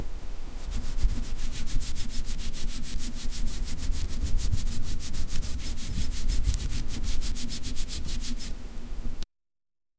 Um som de uma escova de cabelo a esfregar
um-som-de-uma-escova-mhguuc34.wav